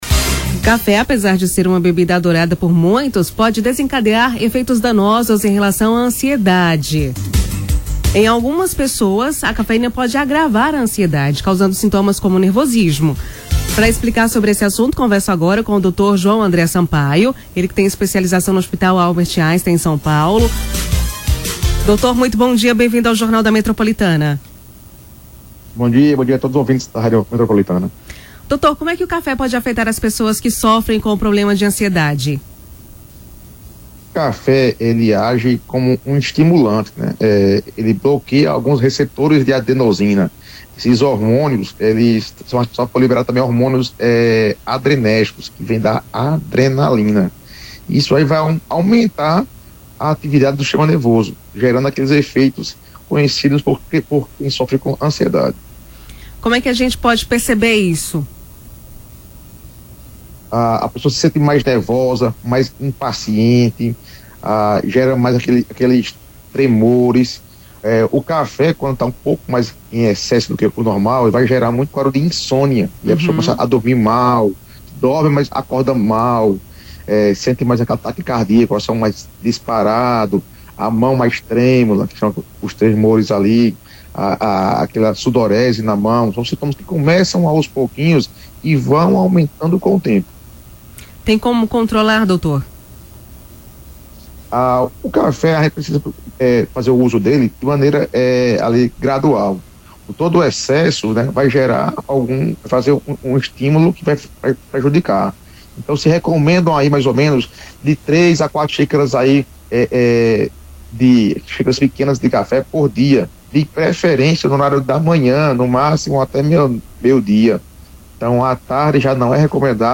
000-ENTREVISTA-CONSUMO-DE-CAFE.mp3